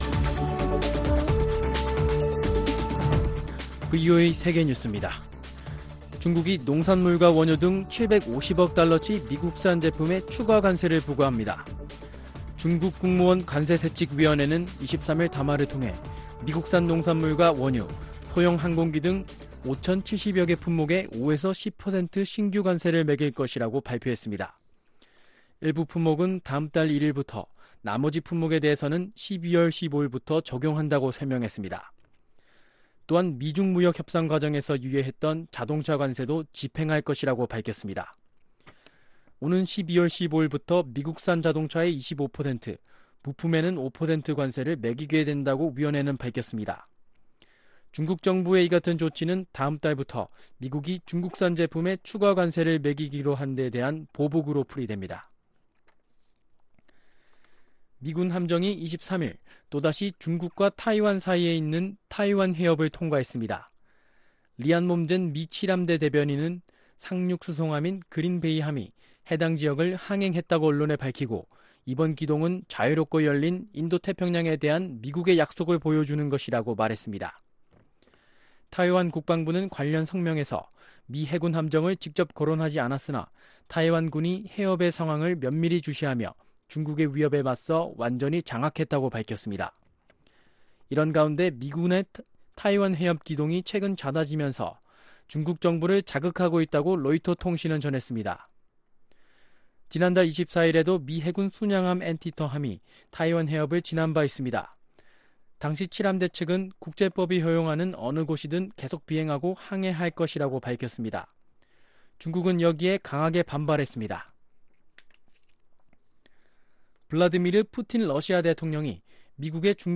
VOA 한국어 아침 뉴스 프로그램 '워싱턴 뉴스 광장' 2019년 8월 24일방송입니다.